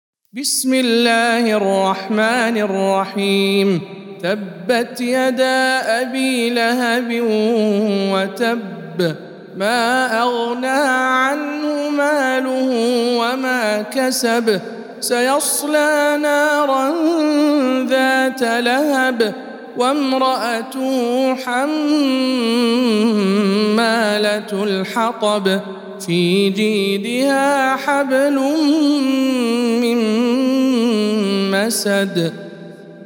سورة المسد - رواية رويس عن يعقوب